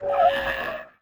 tbd-station-14/Resources/Audio/Voice/Vox/vox_cry.ogg
vox_cry.ogg